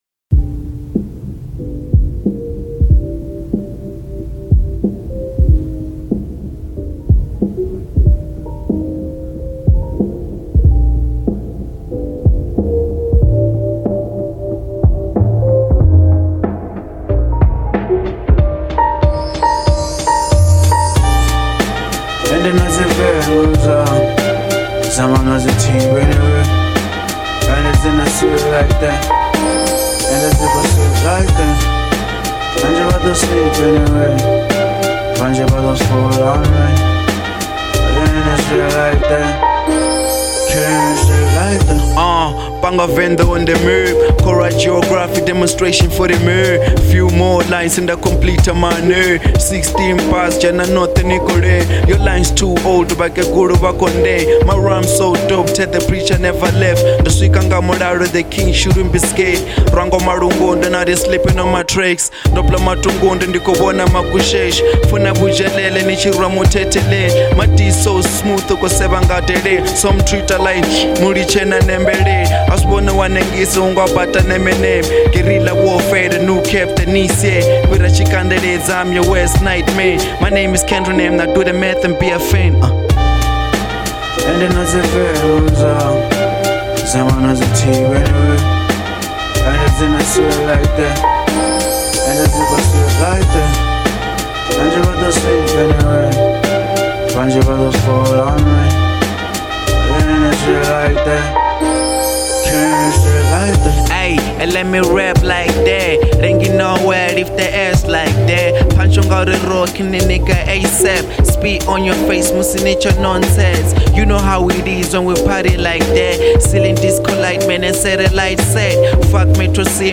03:10 Genre : Venrap Size